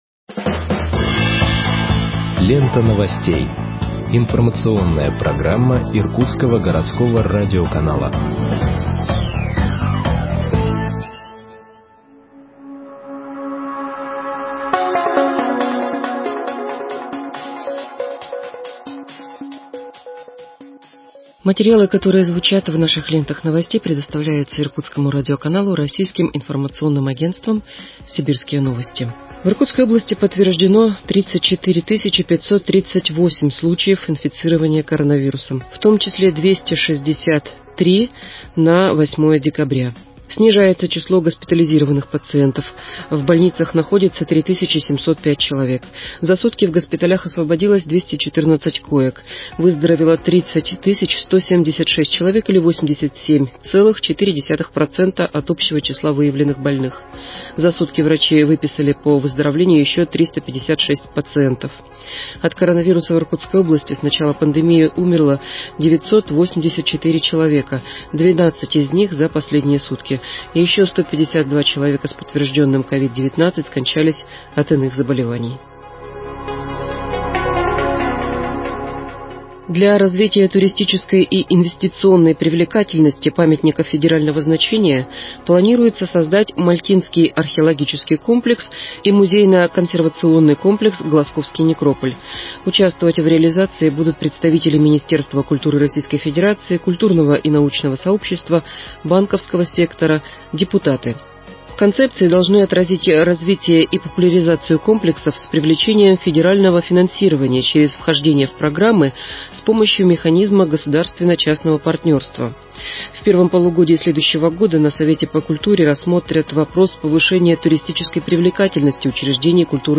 Выпуск новостей в подкастах газеты Иркутск от 09.12.2020 № 2